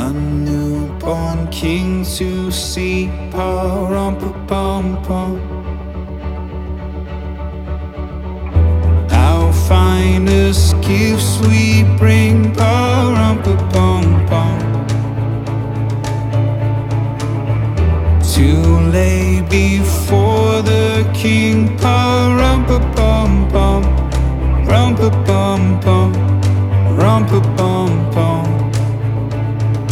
• Christmas
is a Christian pop duo